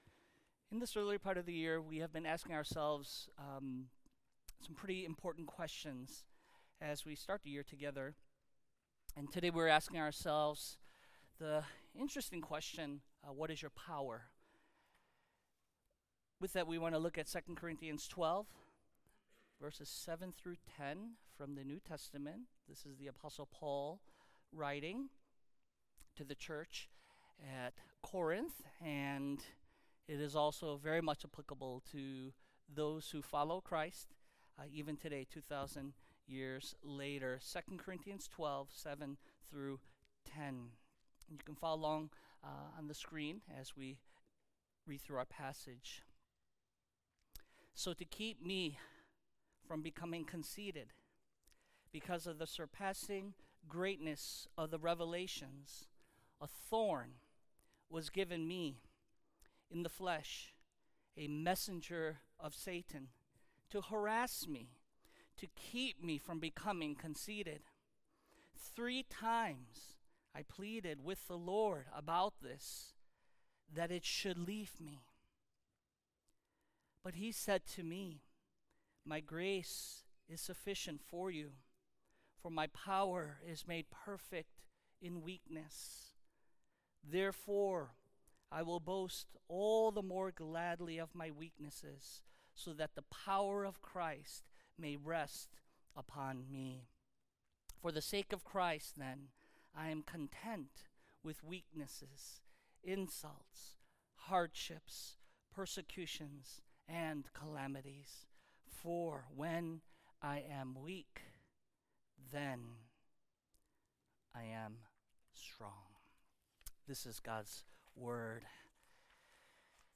speaks on 2 Corinthians 12:7-10